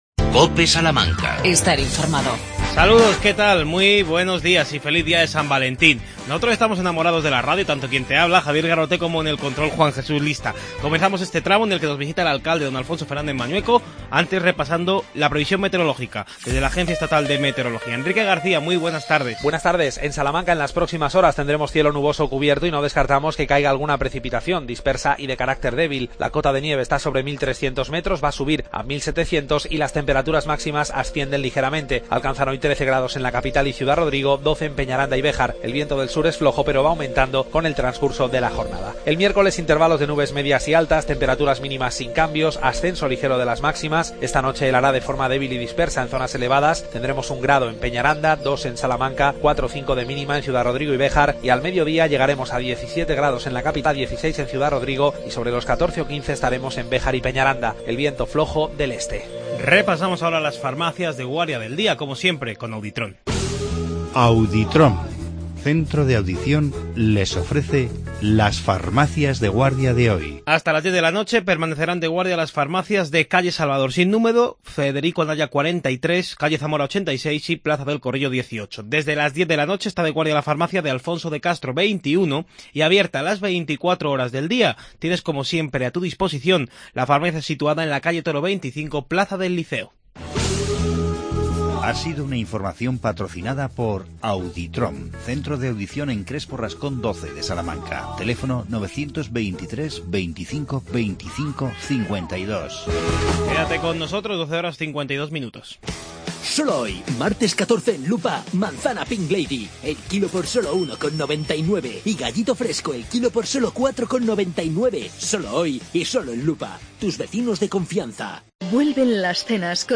AUDIO: Nos ha visitado el alcalde Alfonso Fernández Mañueco.